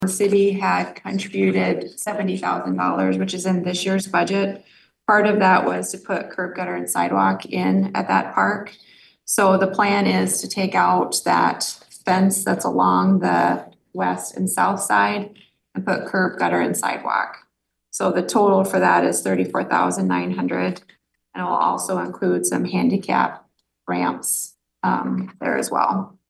talked about a quote for curb, gutter, and sidewalk at the Eastside Play Park.